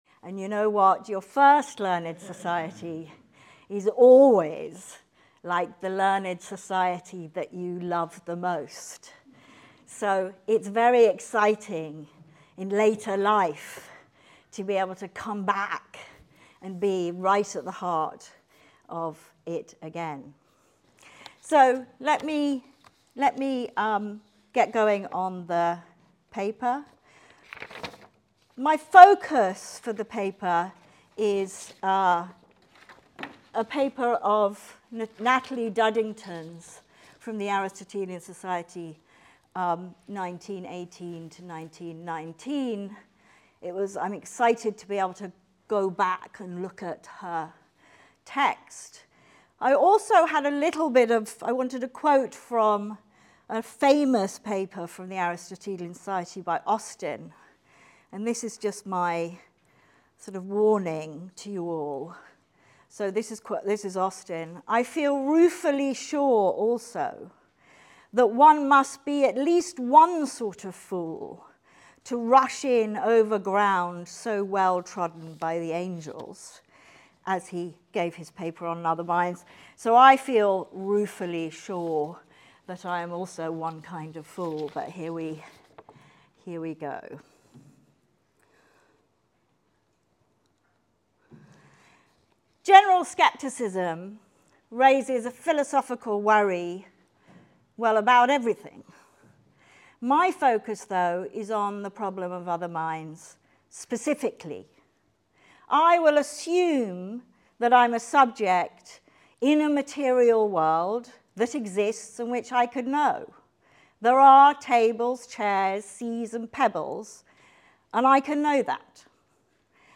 The Aristotelian Society, founded in 1880, meets fortnightly in London to hear and discuss talks given by leading philosophers from a broad range of philosophical traditions.